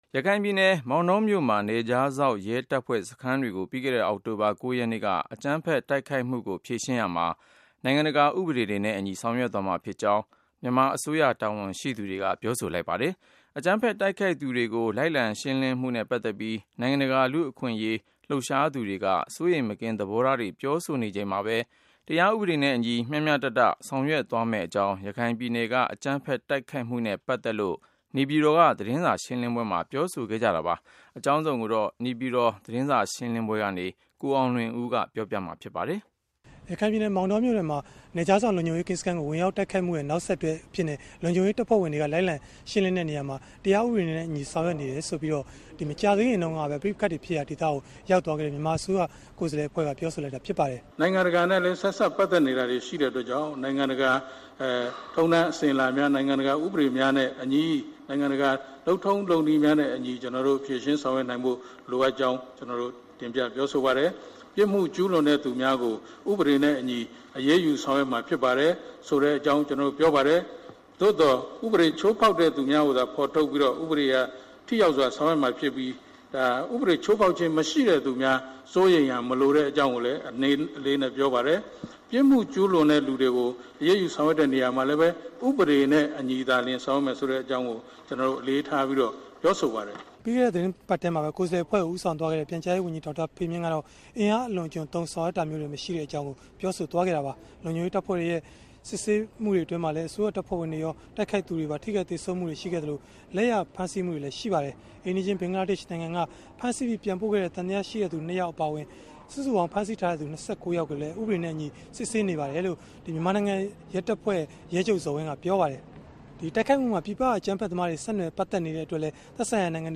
နေပြည်တော် သတင်းစာရှင်းလင်းပွဲကနေ
VOA သတင်းဌာနမှ မေးမြန်းမှု